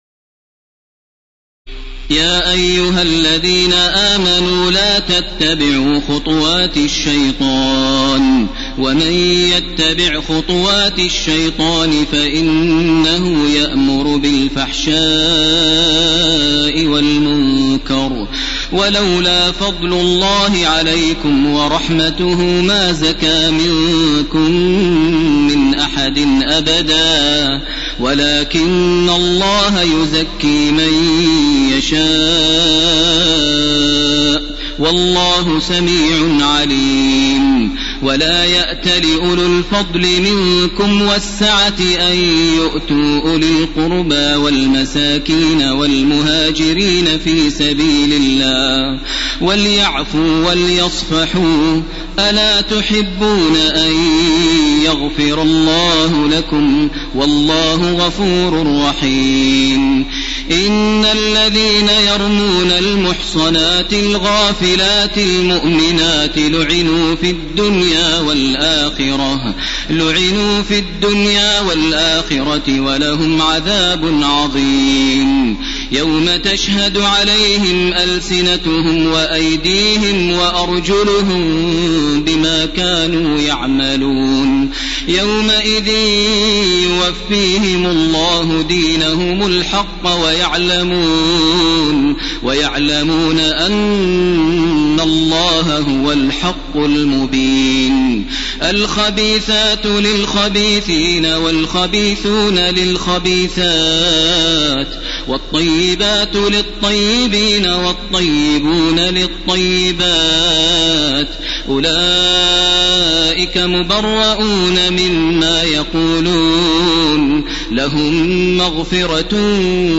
تراويح الليلة الثامنة عشر رمضان 1431هـ من سورتي النور (21-64) و الفرقان (1-20) Taraweeh 18 st night Ramadan 1431H from Surah An-Noor and Al-Furqaan > تراويح الحرم المكي عام 1431 🕋 > التراويح - تلاوات الحرمين